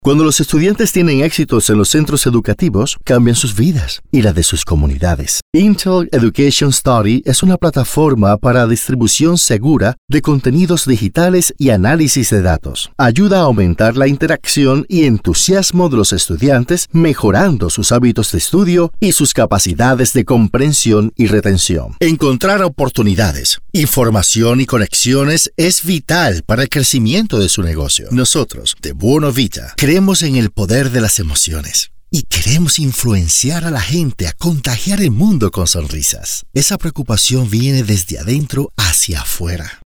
A warm and deep native Latin American Spanish voice ideal for narrations, sexy and fun for commercials, smooth and professional for presentations.
Sprechprobe: eLearning (Muttersprache):